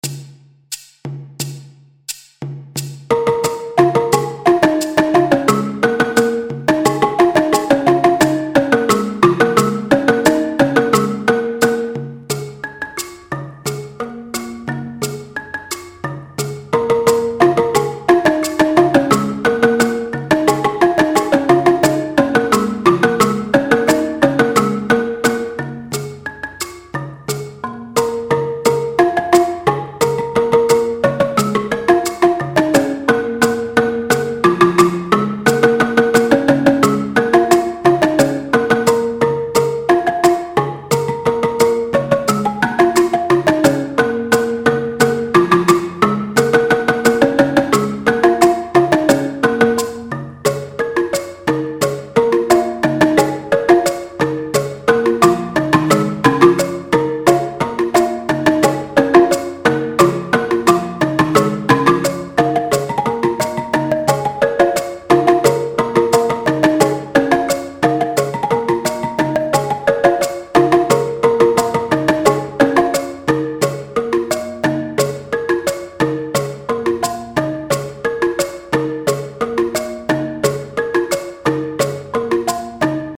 Pentatonische balafoon
176 bpm Arrangement
RitmeMuso-Bwe-176bpm.mp3